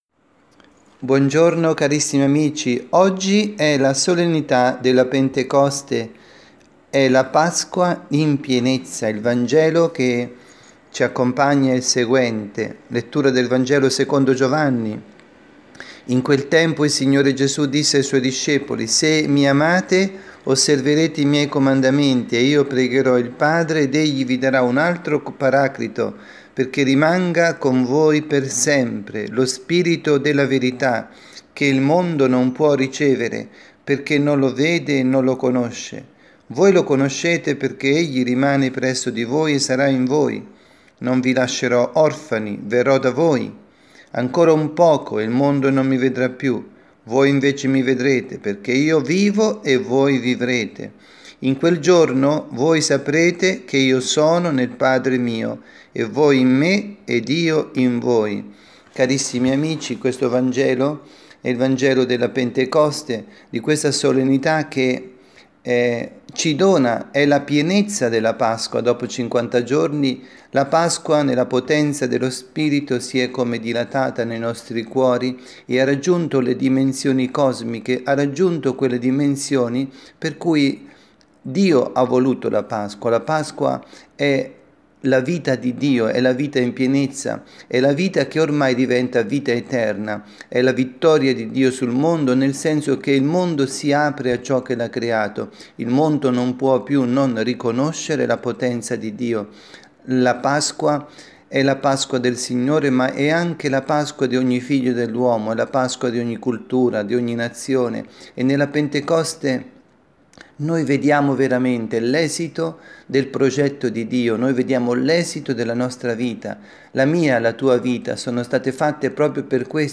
Omelia
dalla Parrocchia S. Rita, Milano – S. Messa della Veglia di Pentecoste